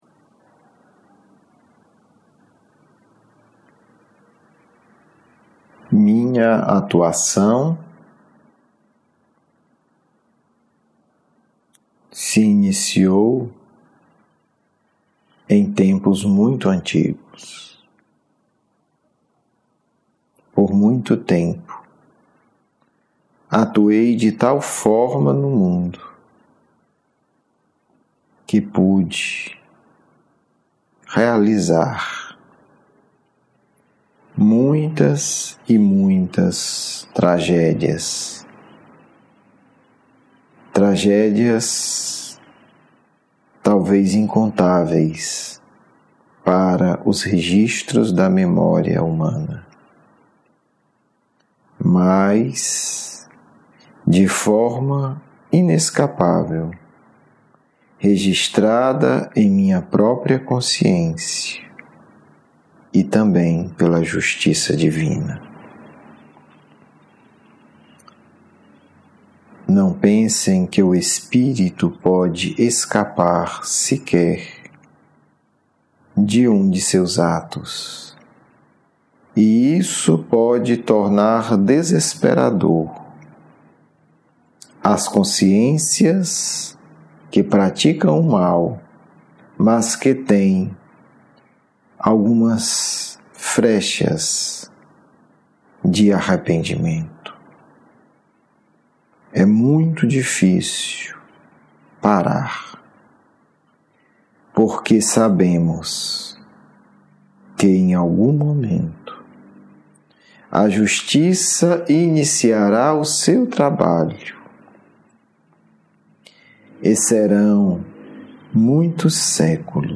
Apresentamos abaixo um depoimento de um espírito que atuou por muitos séculos como um líder das trevas.